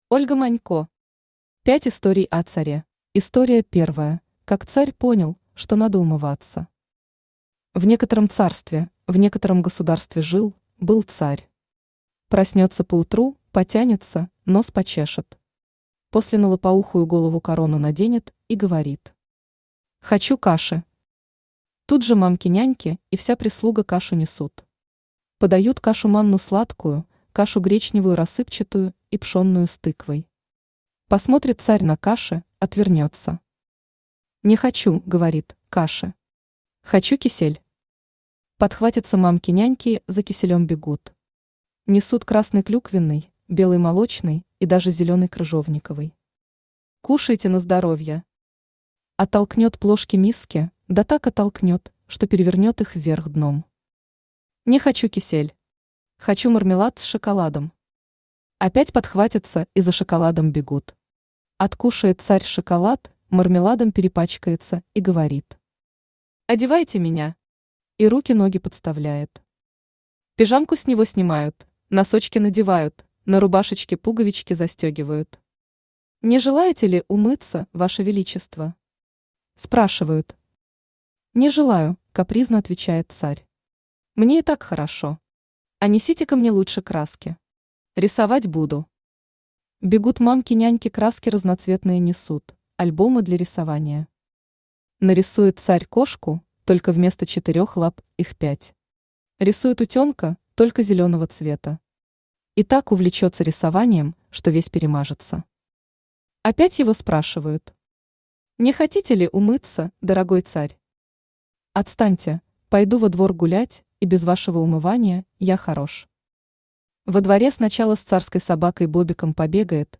Аудиокнига Пять историй о царе | Библиотека аудиокниг
Aудиокнига Пять историй о царе Автор Ольга Владимировна Манько Читает аудиокнигу Авточтец ЛитРес.